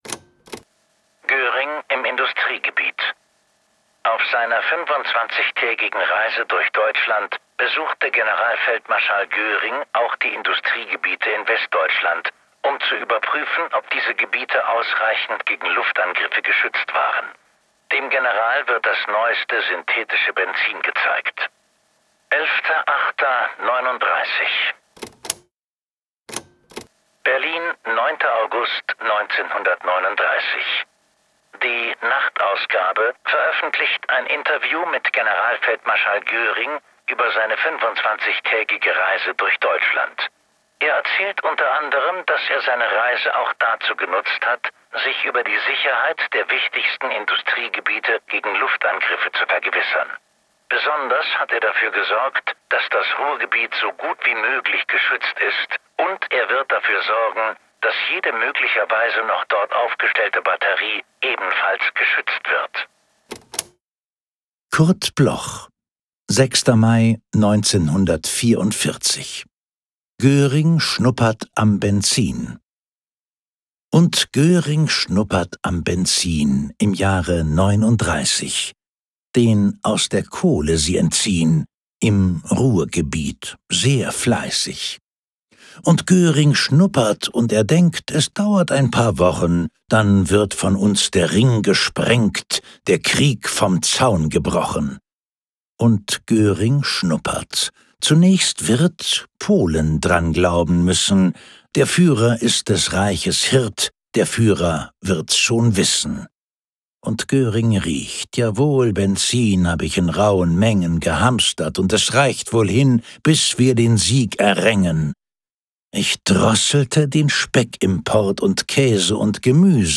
vorgetragen von